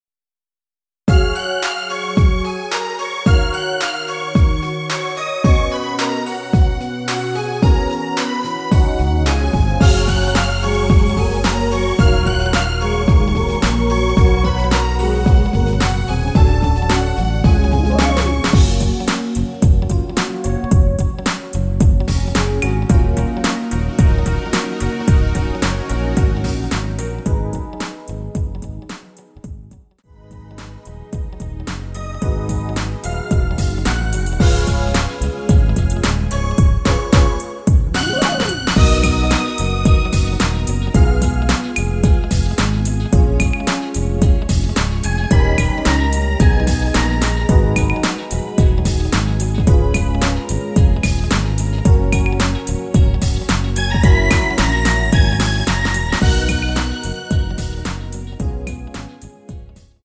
Gb
◈ 곡명 옆 (-1)은 반음 내림, (+1)은 반음 올림 입니다.
앞부분30초, 뒷부분30초씩 편집해서 올려 드리고 있습니다.